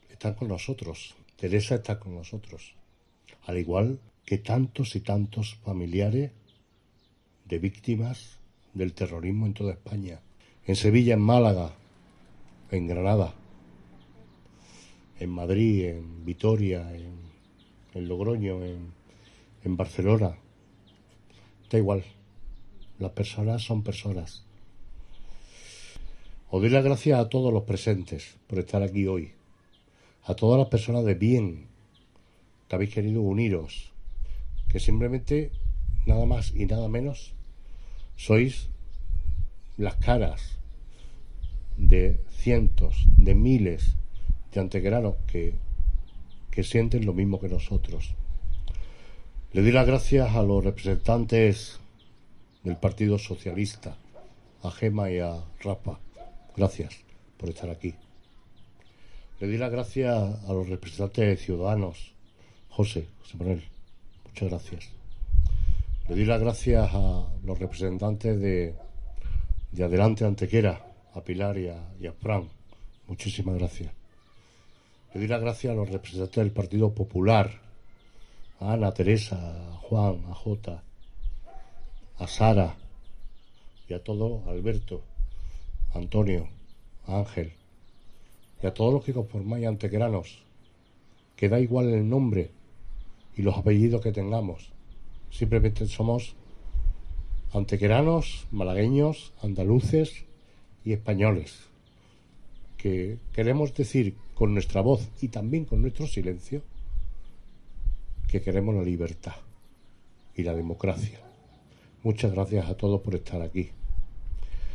Antequera homenajea a las víctimas del terrorismo con un emotivo acto desarrollado en los Jardines Miguel Ángel Blanco en la conmemoración del vigésimo segundo aniversario de su asesinato y en presencia de Teresa Jiménez Becerril
Cortes de voz